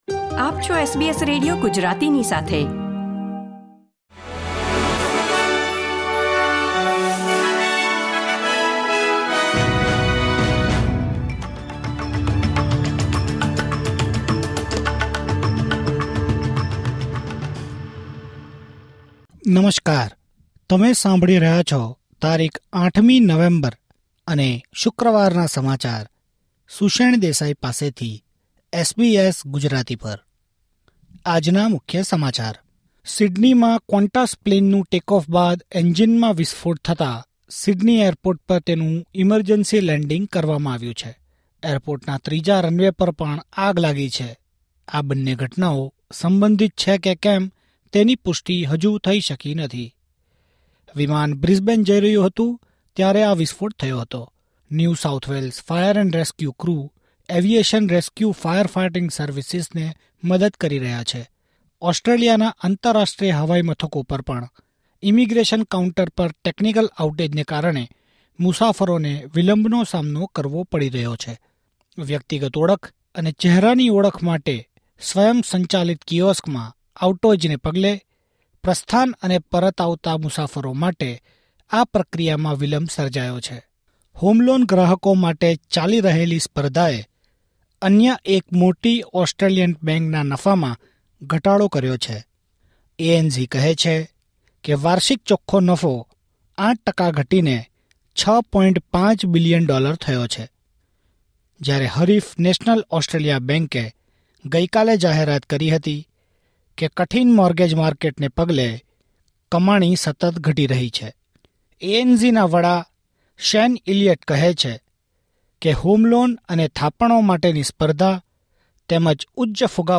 SBS Gujarati News Bulletin 8 November 2024